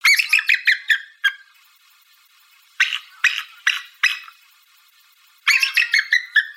Bald Eagle Call
A great bald eagle call requested by anonymous user.